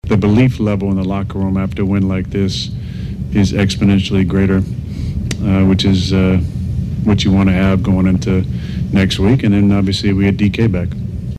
Rodgers says the win gives the Steelers the confidence they will need to take on the Texans.
nws0585-aaron-rodgers-we-believe.mp3